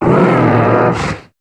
Cri de Bourrinos dans Pokémon HOME.